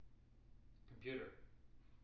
wake-word
tng-computer-331.wav